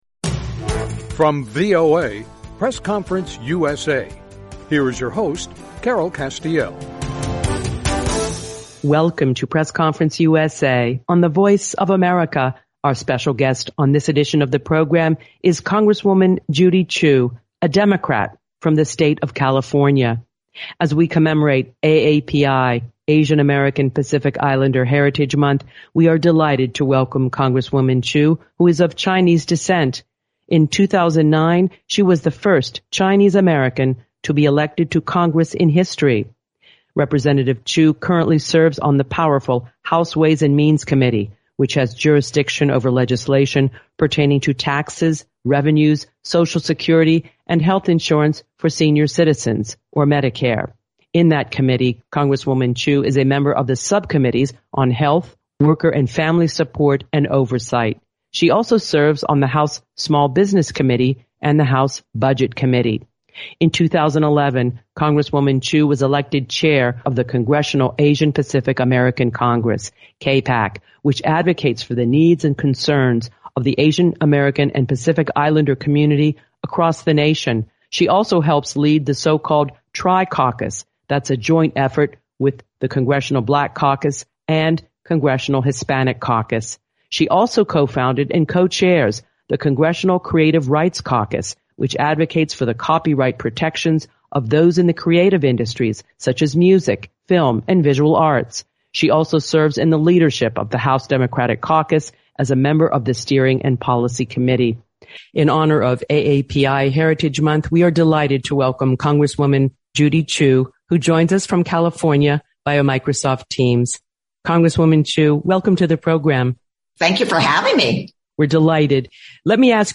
talks with Rep. Judy Chu, the first Chinese American woman elected to Congress. A Democrat from California, Chu discusses the COVID-19 Hate Crimes Act which curbs violence toward Asian Americans, gun violence, and abortion rights.